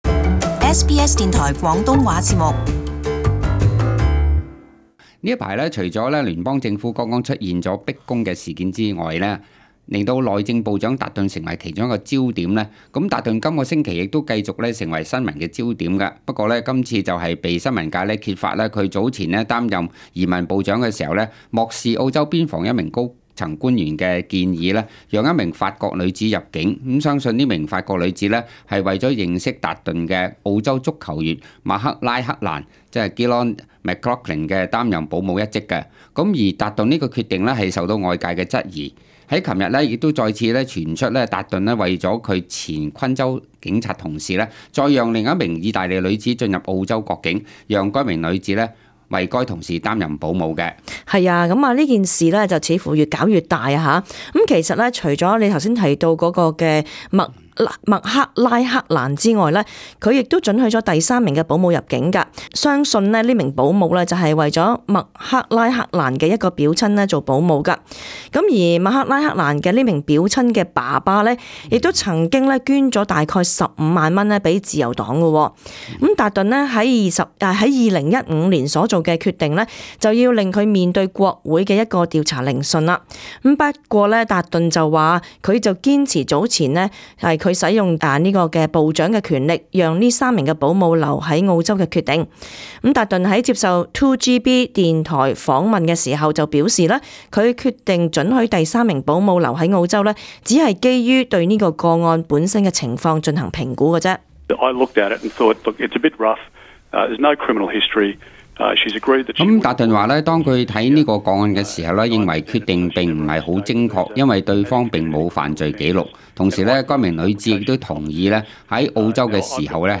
【時事報導】達頓讓意籍女子入澳任保姆受質疑